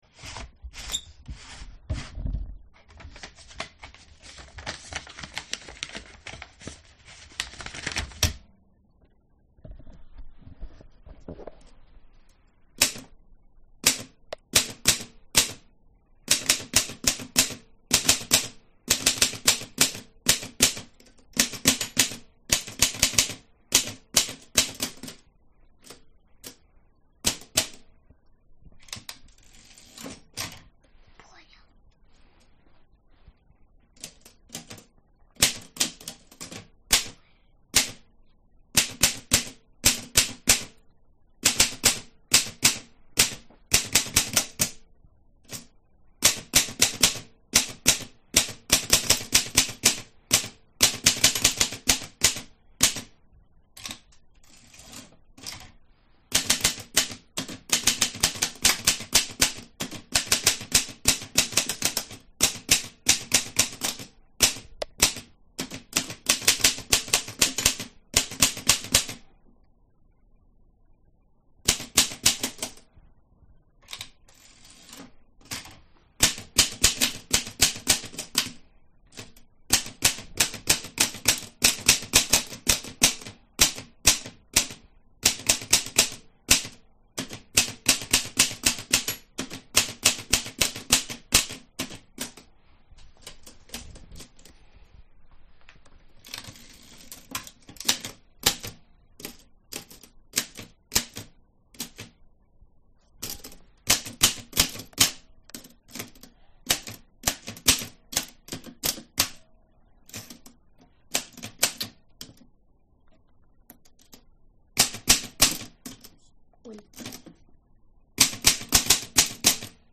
Здесь собраны характерные эффекты: от ритмичного стука клавиш до металлического звона каретки.
Звук полного цикла: подготавливаем бумагу, печатаем на винтажной пишущей машинке